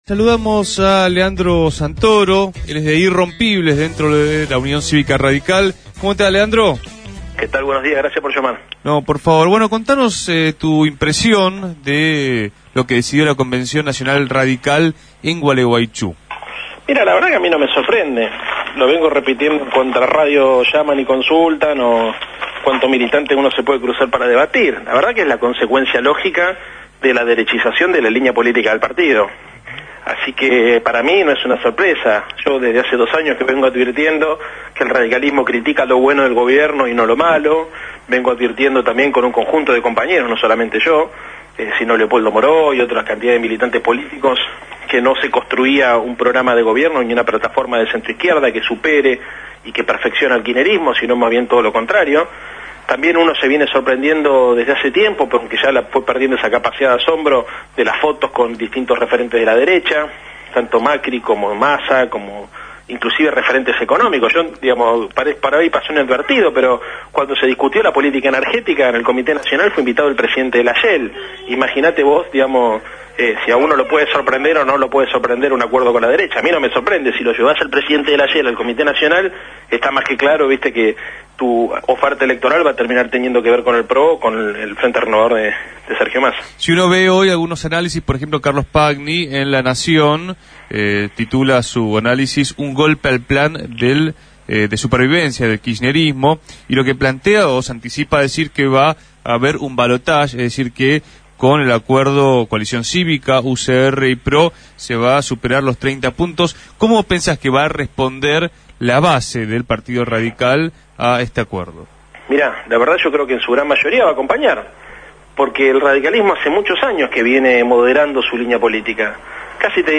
Entrevistado en el aire de Radio Gráfica, dio su visión acerca de como quedan posicionados los principales dirigentes del partido.